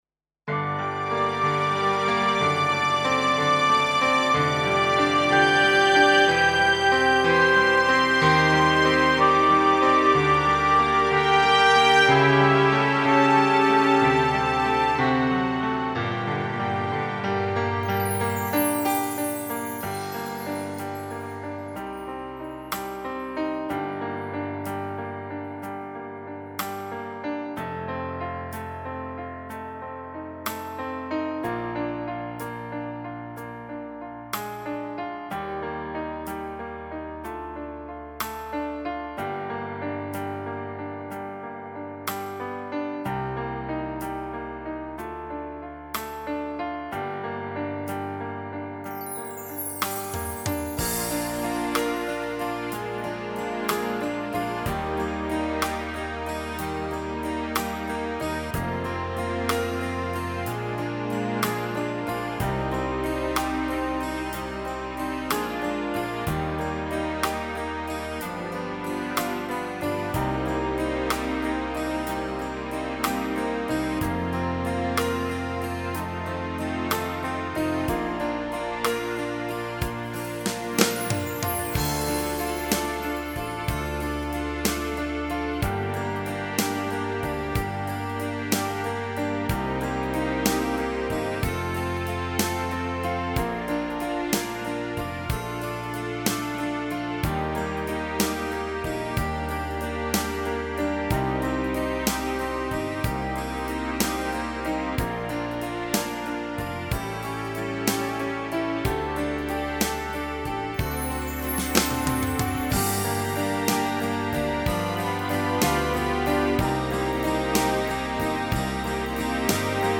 •   Beat  02.